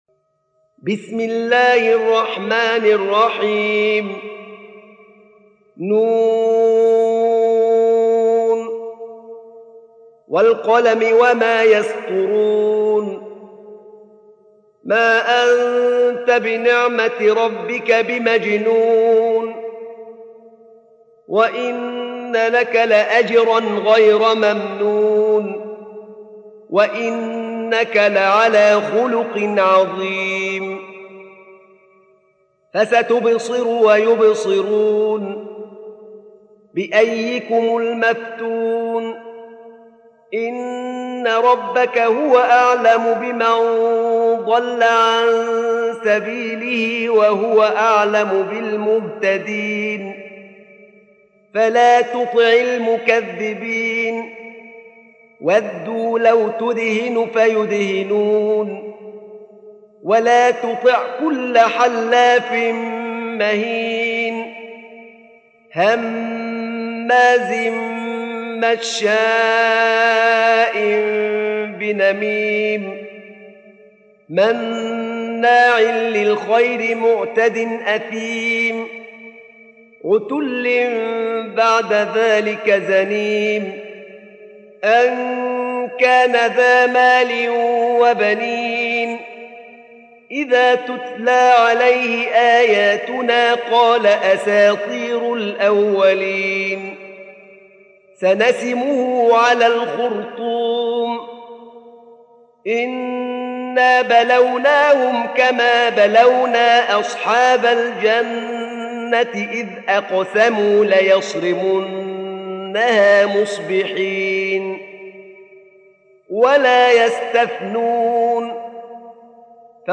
سورة القلم | القارئ أحمد نعينع